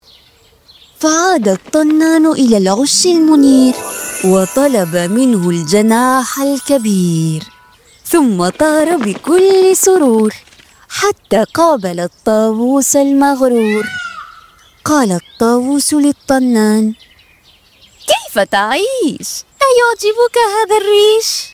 Livre audio enfant sur l’acceptation de soi – Ouch Al Amani | Yaasoub Editions
Narrateur